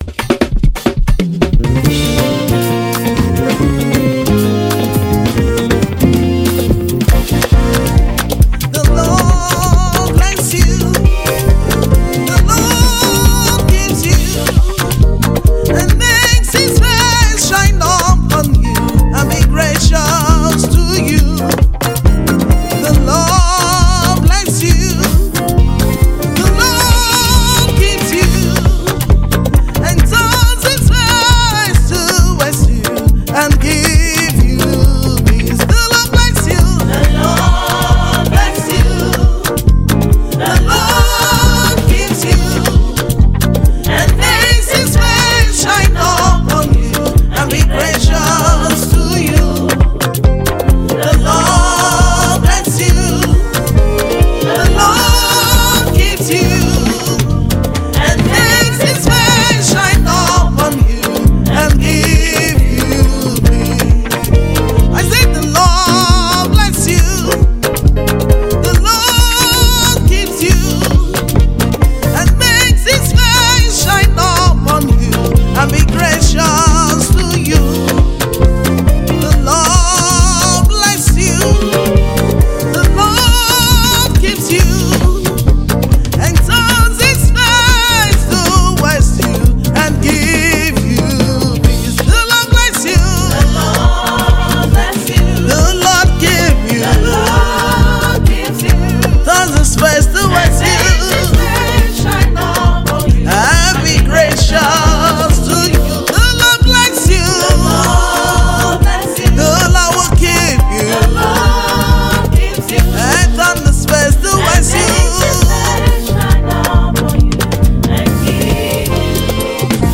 A Prophetic Anthem of Freedom and Generational Blessings
New gospel release 2025
Christian music of hope
Worship songs about blessings